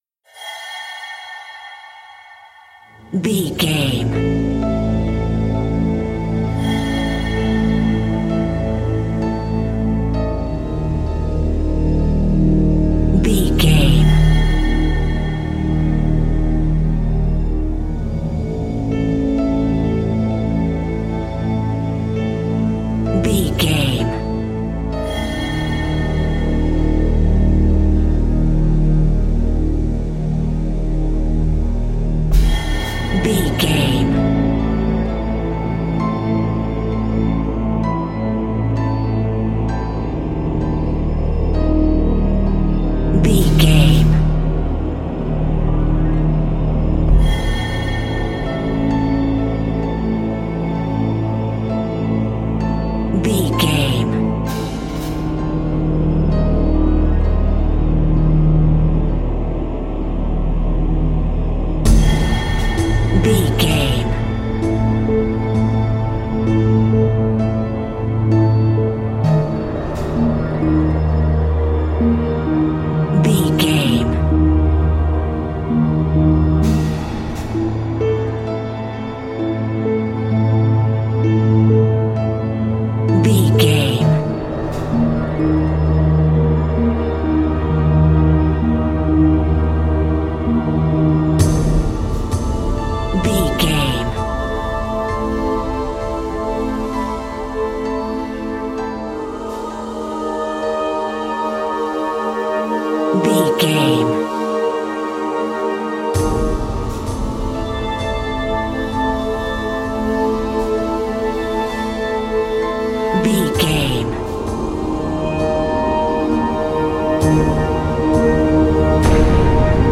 Aeolian/Minor
synthesiser
strings
percussion
ominous
dark
suspense
haunting
creepy